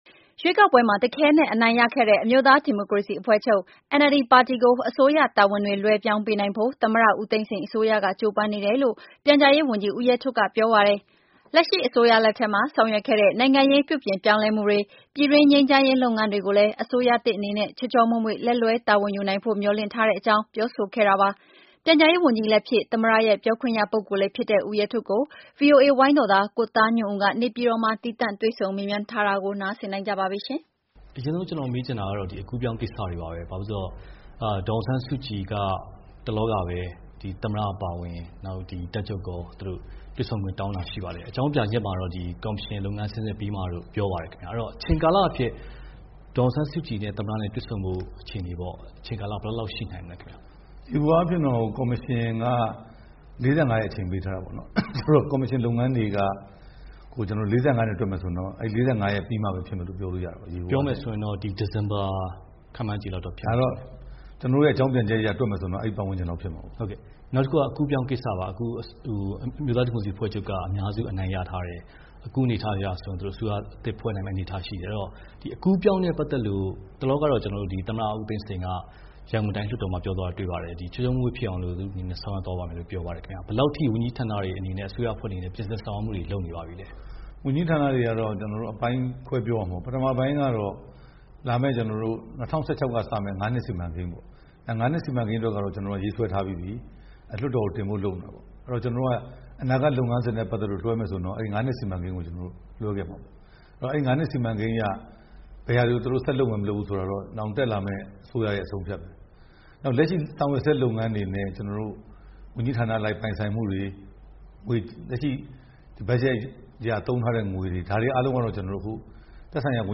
ပြန်ကြားရေးဝန်ကြီး ဦးရဲထွဋ်နဲ့ ဗွီအိုအေ တွေ့ဆုံမေးမြန်းချက်